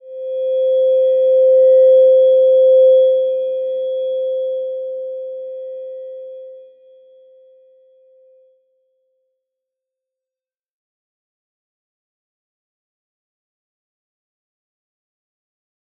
Slow-Distant-Chime-C5-mf.wav